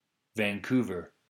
1. ^ /vænˈkvər/
van-KOO-vər; Canadian French: [vãkuvaɛ̯ʁ]; Halkomelem: lhq’á:lets
EN-Vancouver.ogg.mp3